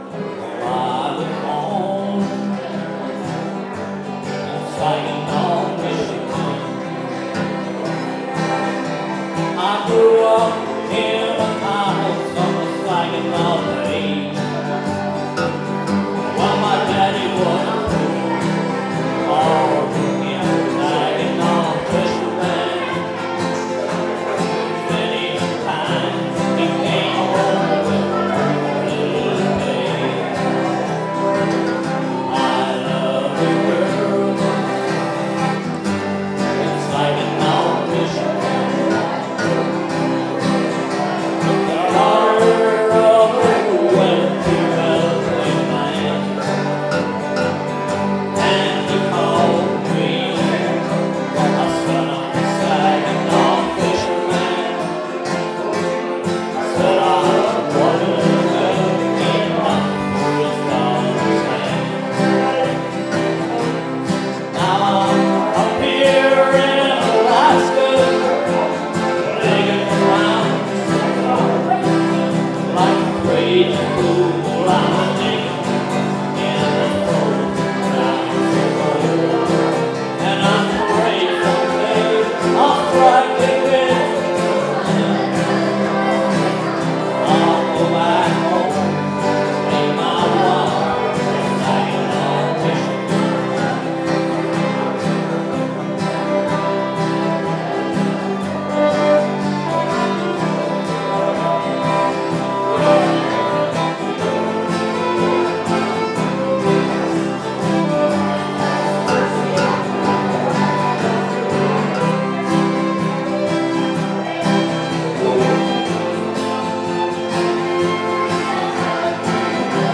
4 pm: York Hall potluck and jam, York
It’s hard to get good sound when recording in community halls where there’s lots of room reverb from the floor and walls but all the same
vocals, guitar
accordion
fiddle